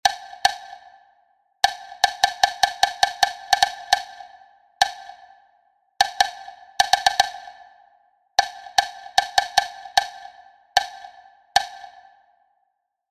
secuenciaritmicasud10.1.mp3